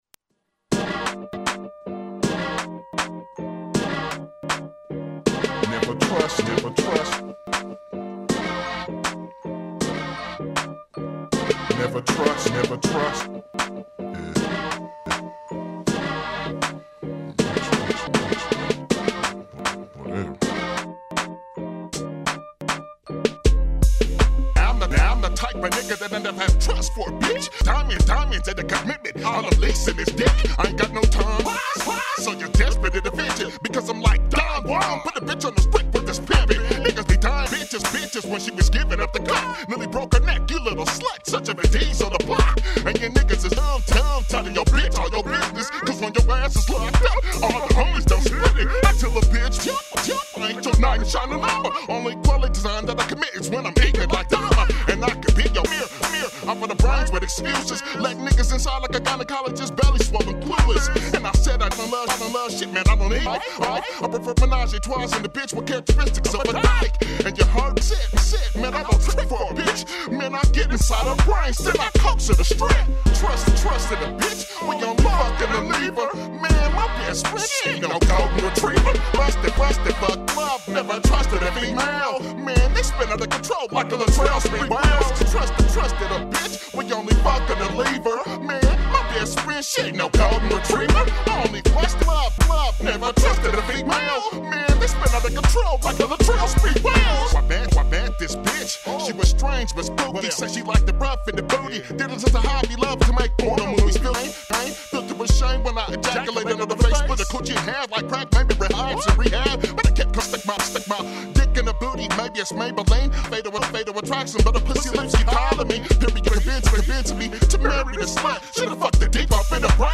Hip-hop
Rhythm & Blues